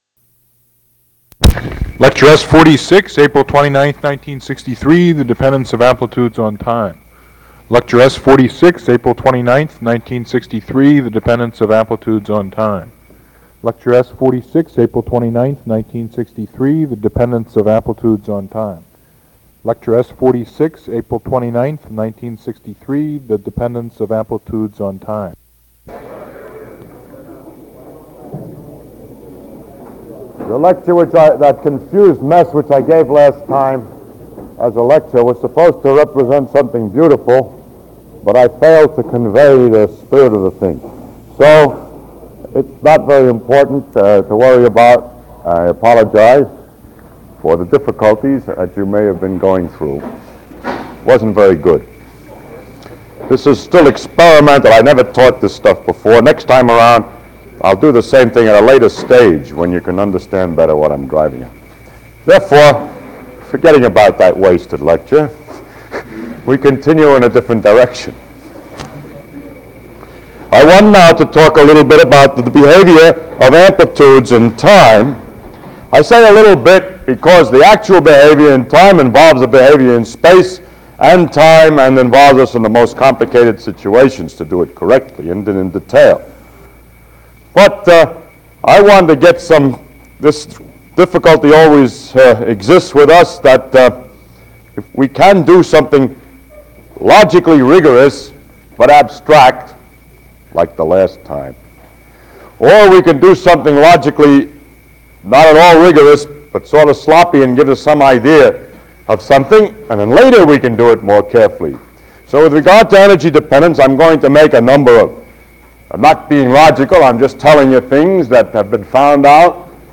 양자 역학 강의 녹음